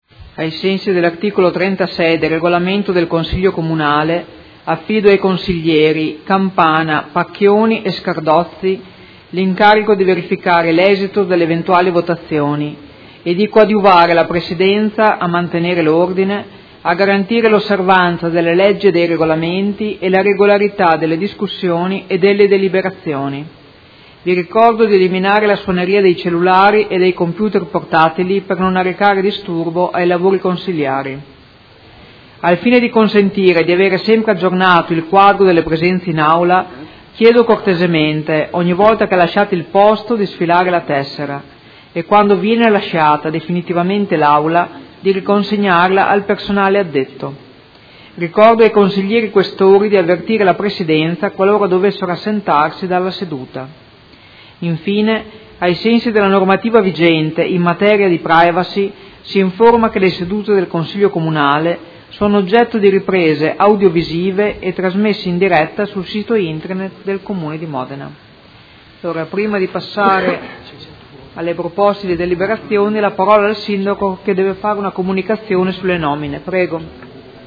Seduta del 30/03/2017. Apertura lavori